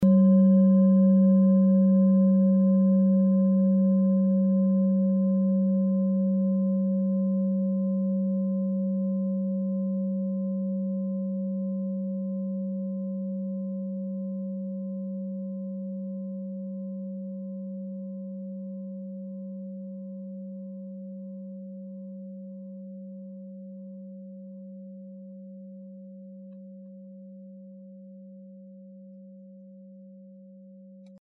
Klangschale Orissa Nr.5
Sie ist neu und wurde gezielt nach altem 7-Metalle-Rezept in Handarbeit gezogen und gehämmert.
Wenn man die Frequenz des Mittleren Sonnentags 24mal oktaviert, hört man sie bei 194,18 Hz.
Auf unseren Tonleiter entspricht er etwa dem "G".
klangschale-orissa-5.mp3